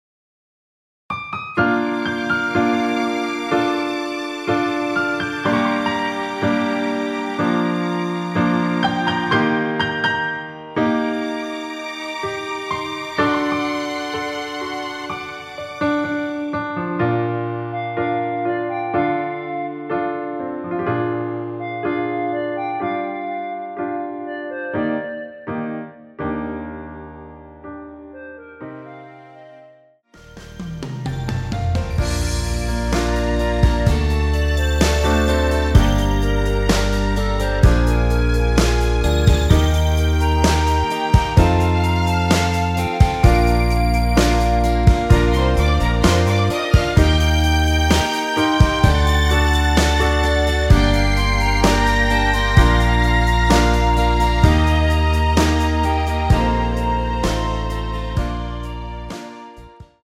원키에서(+5)올린 멜로디 포함된 MR입니다.
Bb
앞부분30초, 뒷부분30초씩 편집해서 올려 드리고 있습니다.
중간에 음이 끈어지고 다시 나오는 이유는